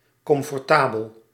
Ääntäminen
US : IPA : [ˈkʌmf.tə.bəl]